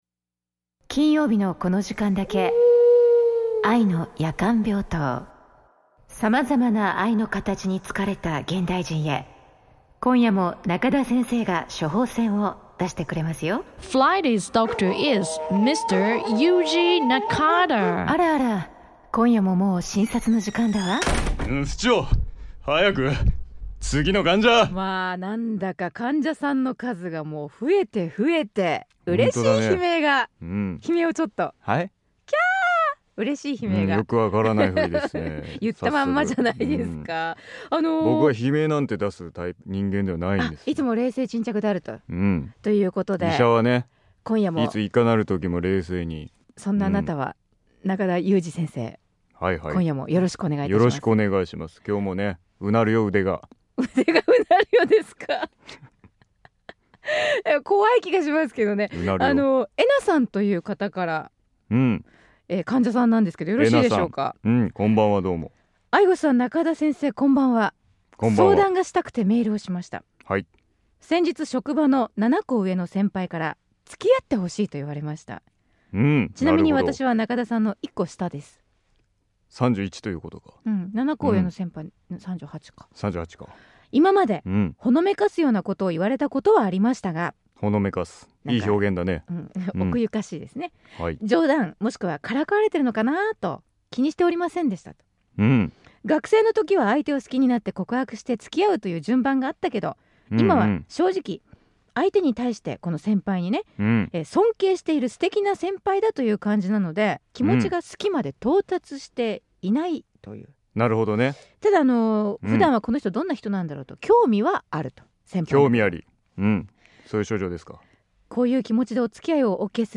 公開録音(後編)は、 入場時お客さんに貼ってもらったトークテーマから "2020年東京オリンピック出場説"で大困惑！？ さらに「最初はグー？オ～ライ？」のジャンケン大会。